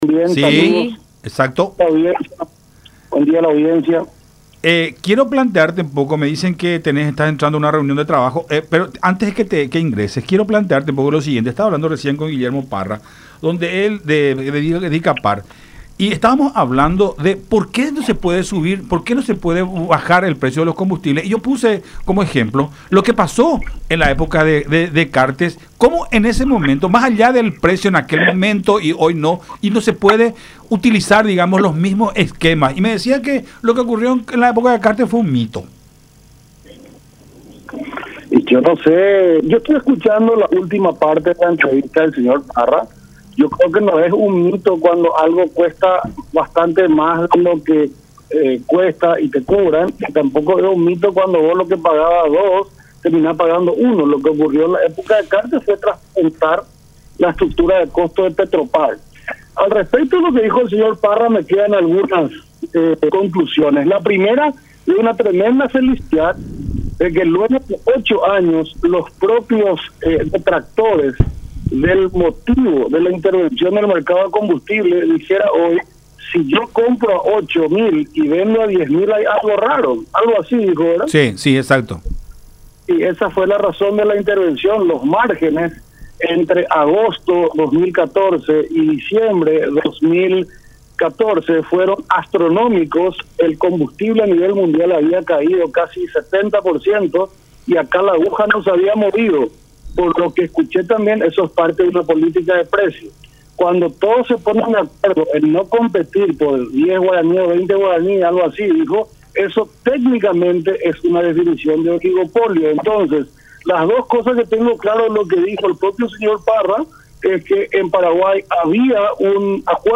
Gustavo Leite, exministro de Industria y Comercio.